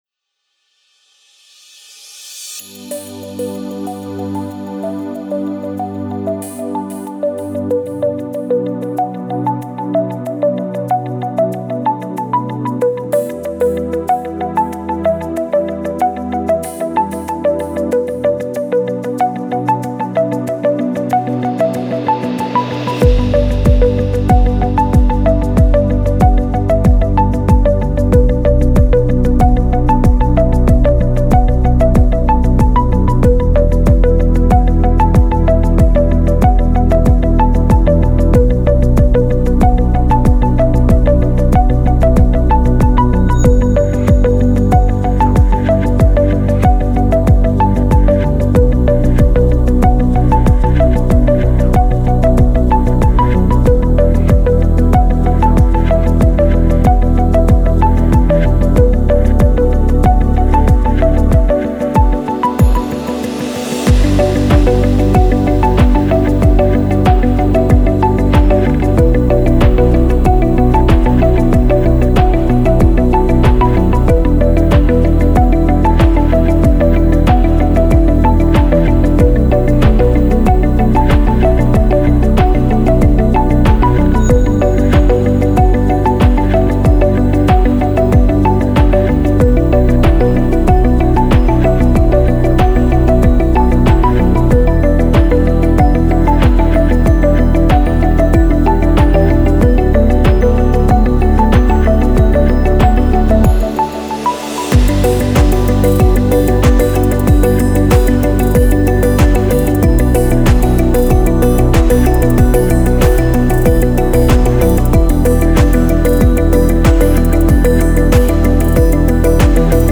InspiringChilloutAmbient.mp3